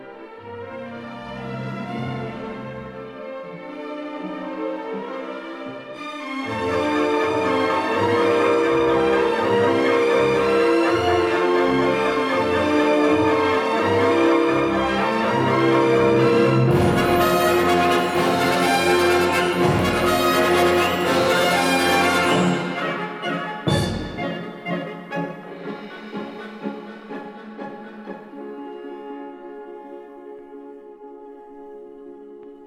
A 1958 stereo recording